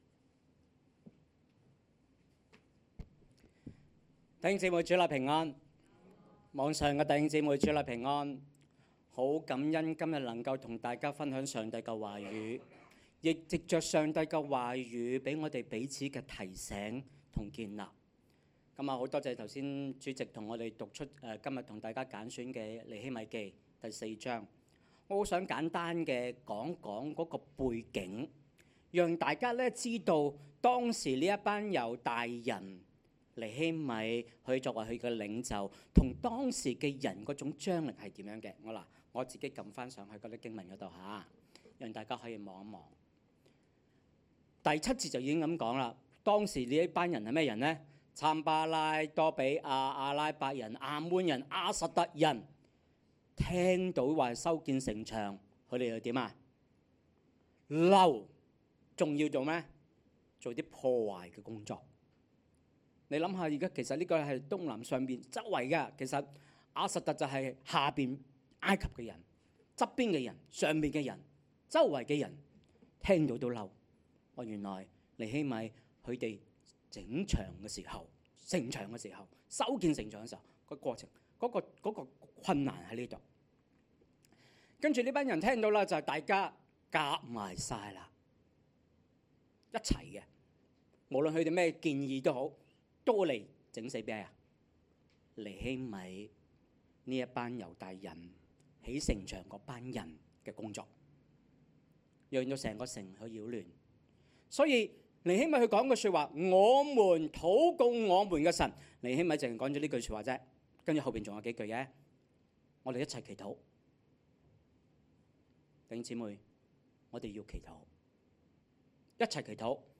Sermon_2025_1019.mp3